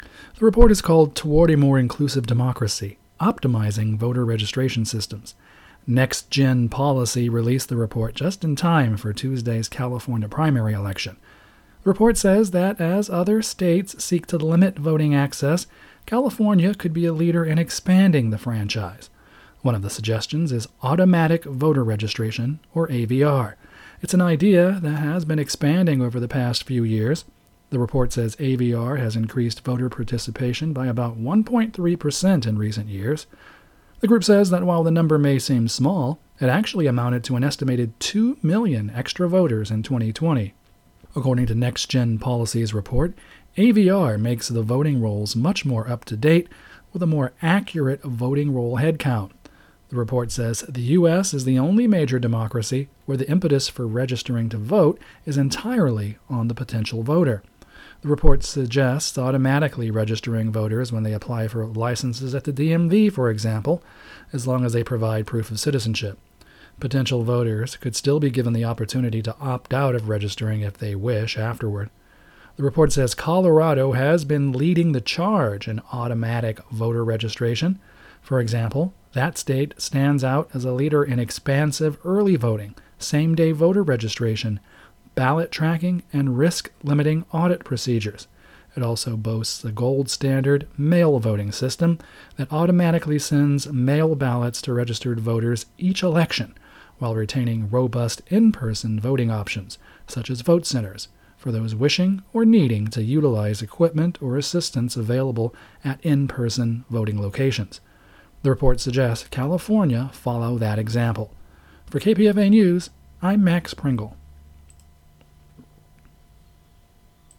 The Pacifica Evening News, Weekdays